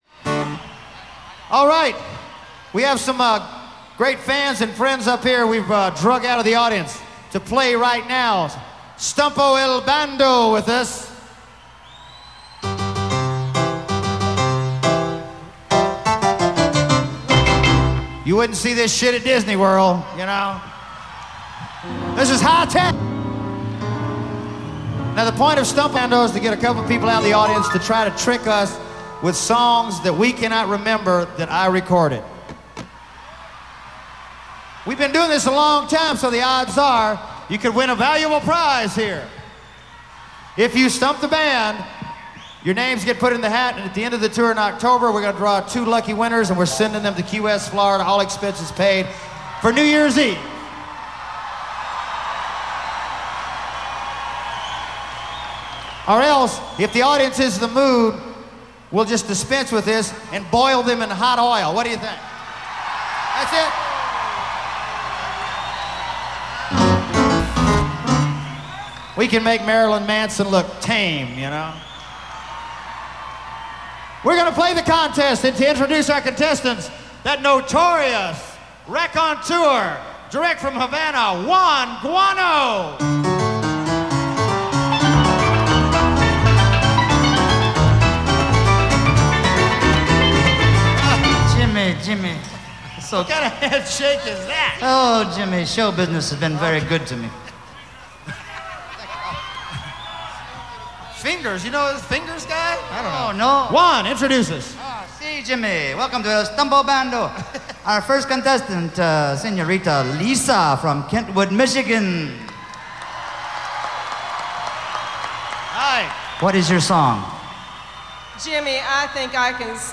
Pine Knob, Clarkston MI